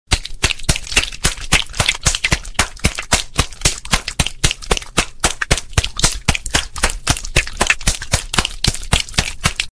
Clap Wet Sound Effect Free Download
Clap Wet